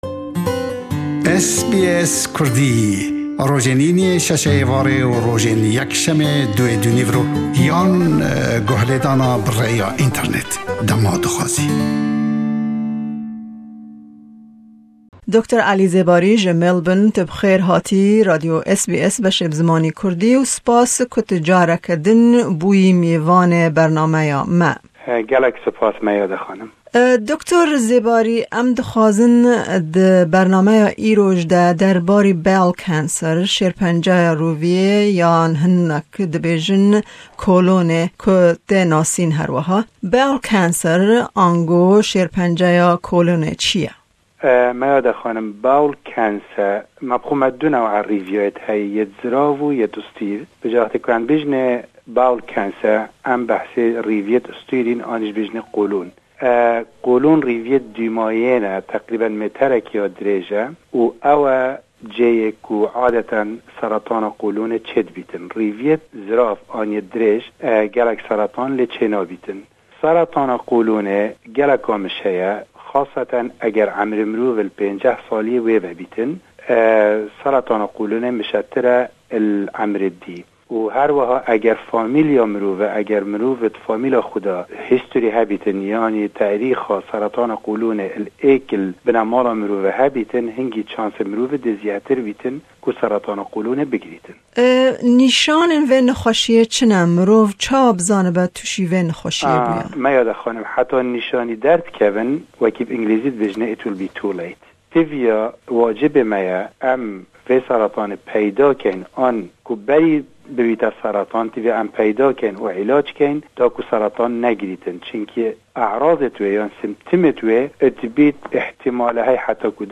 Me li ser wê babetê hevpeyvînek